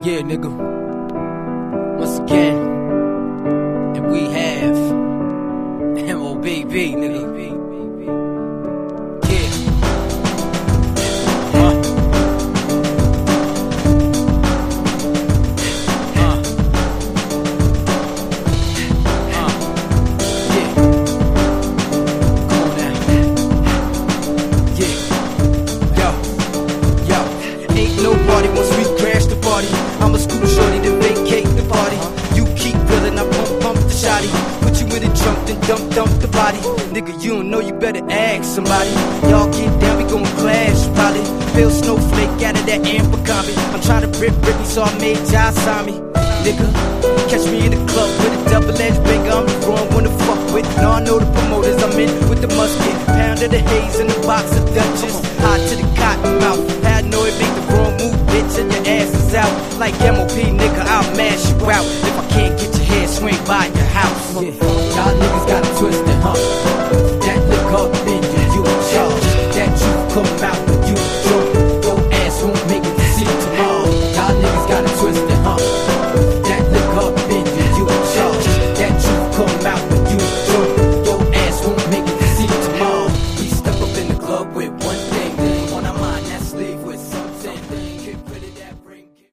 102 bpm